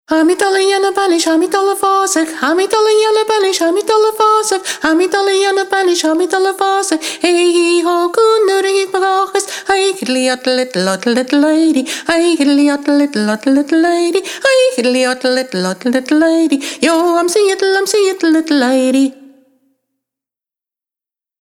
Trad
Gaelic Music Download Tha mi dol a dhèanamh banais MP3